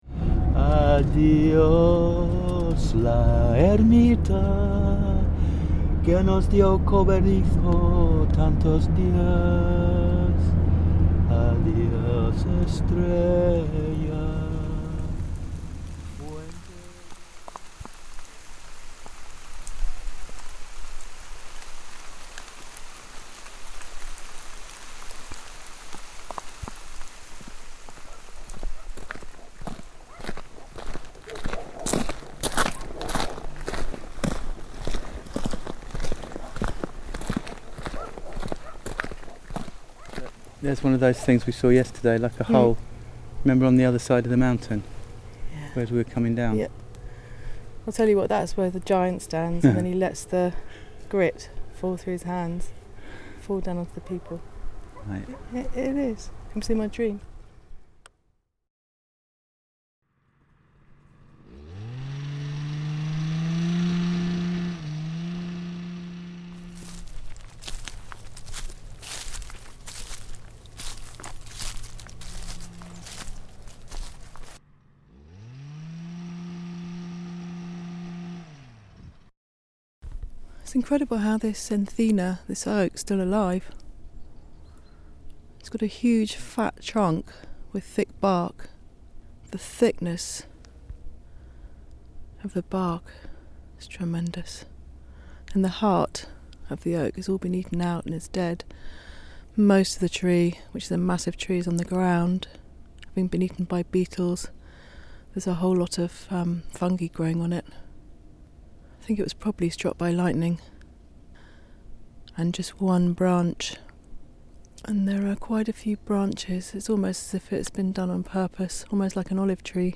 This is episode 1 of an Immersive sonic adventure. A creative collage of sound and voice collected in Sierras Subbéticas Geopark in Southern Spain 2011.